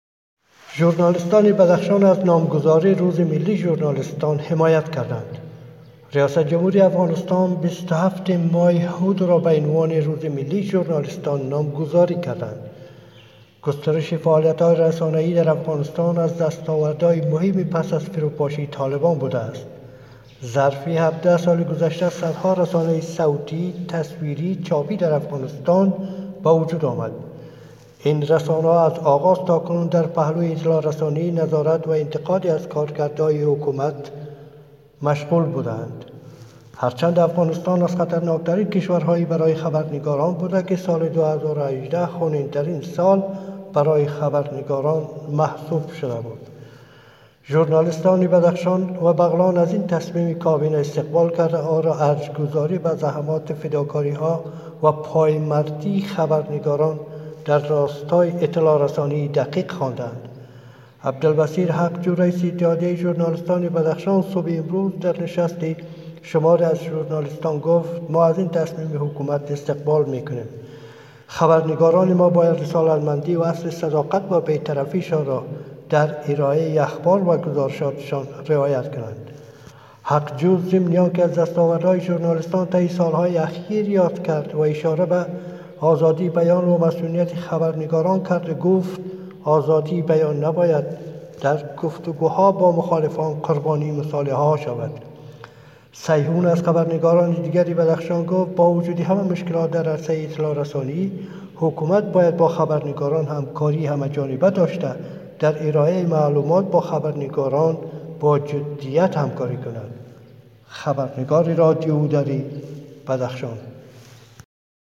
خبر / افغانستان